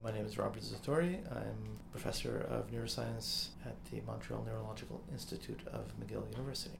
As an enthusiast of psychomusicology, I had the enviable opportunity to speak with Dr. Robert Zatorre and to discuss how he got started on his work. In this first  clip Dr. Zatorre introduces himself: